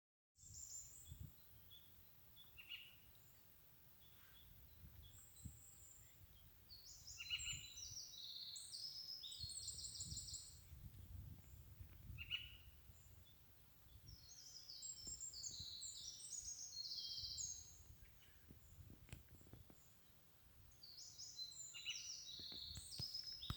Birds -> Orioles ->
Golden Oriole, Oriolus oriolus
StatusVoice, calls heard